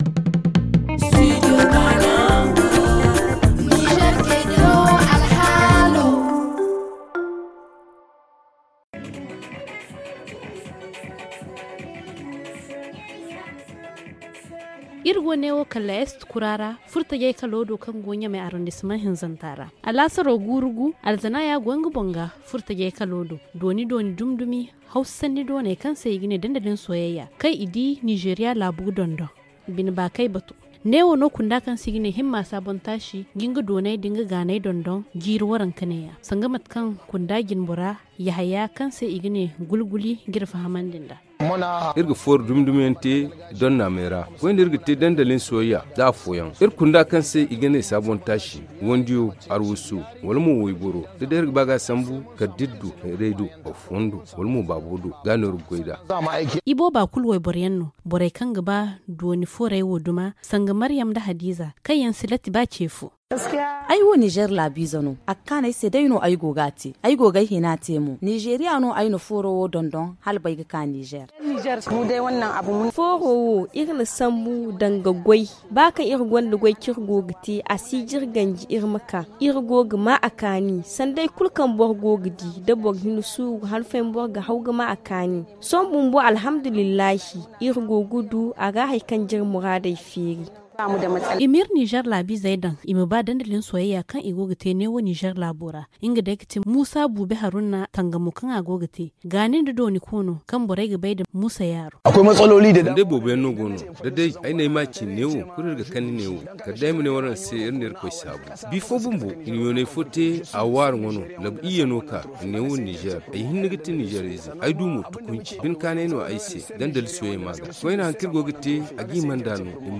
dans ce reportage
Le magazine en zarma